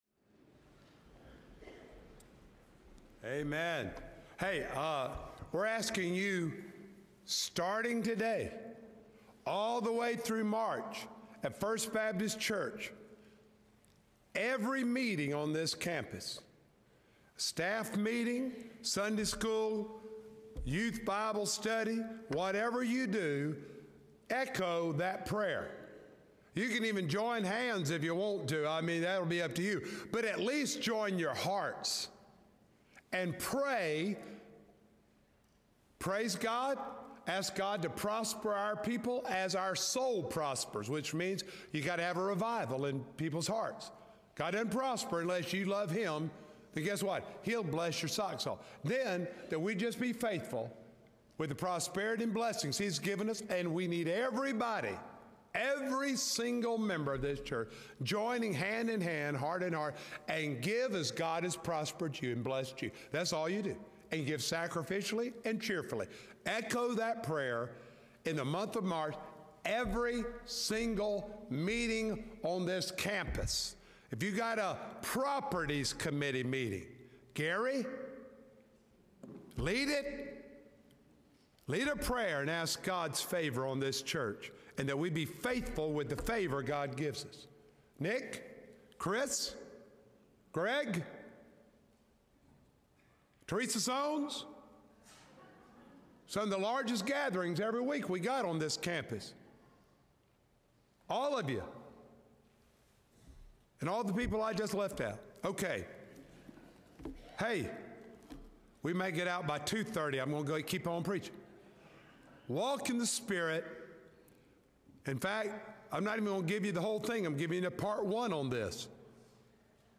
Sermons
March-2-2025-Sermon-Audio.mp3